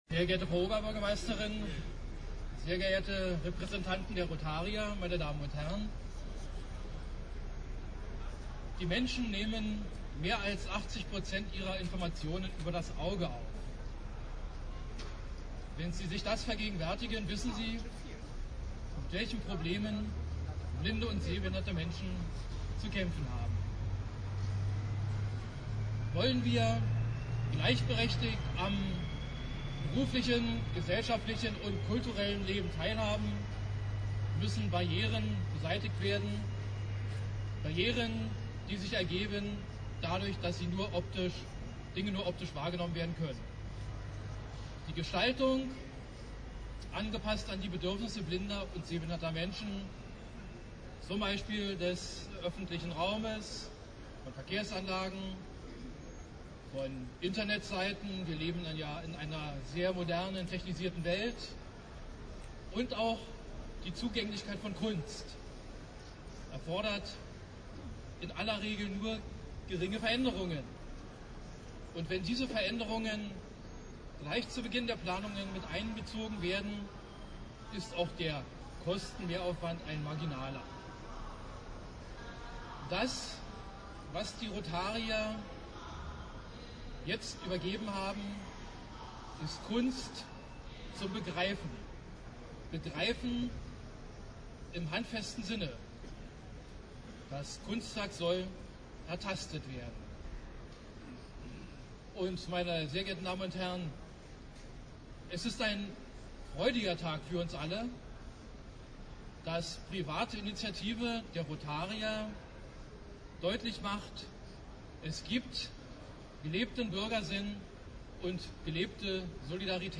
Die abhörbaren Ausschnitte sind in kopfbezogener Stereophonie aufgenommen worden. Es empfihelt sich daher, ihnen unter Kopfhörern zu lauschen.